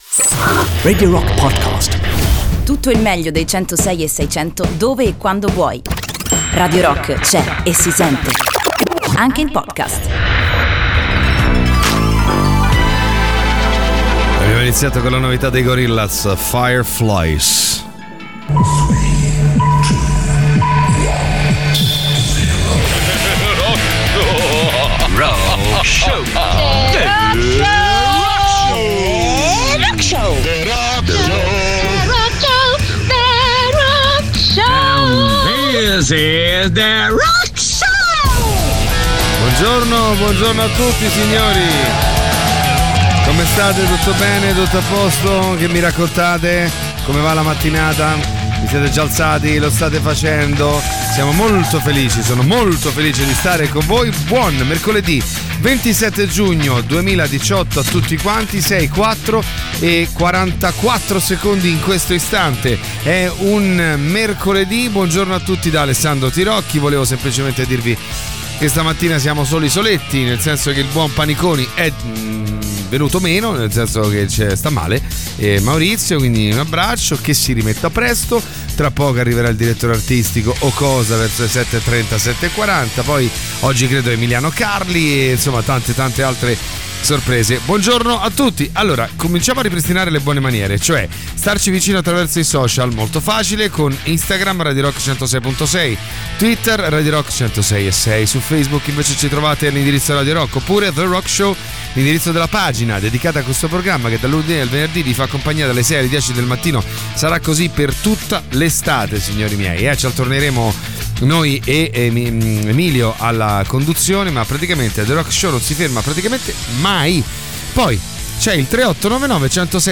in diretta dalle 06.00 alle 08.00 dal Lunedì al Venerdì sui 106.6 di Radio Rock.